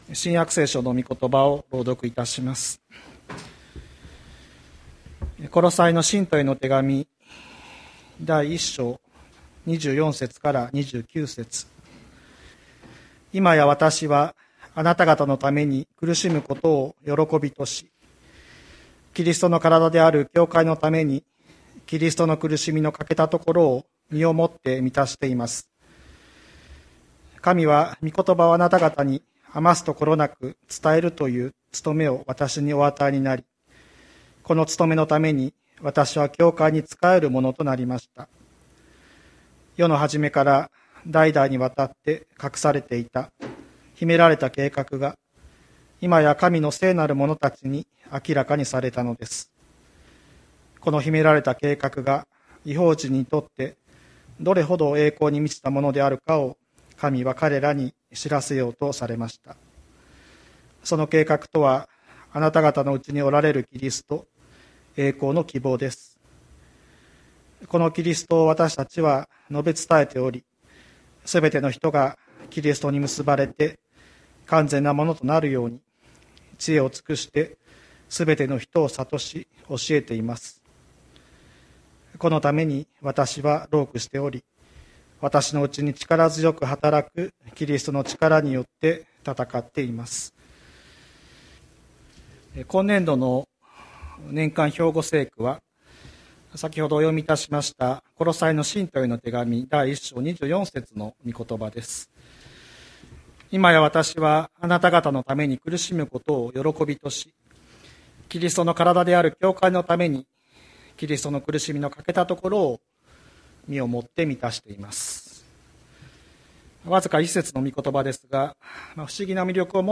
2022年01月30日朝の礼拝「キリストの苦しみを満たす教会」吹田市千里山のキリスト教会
千里山教会 2022年01月30日の礼拝メッセージ。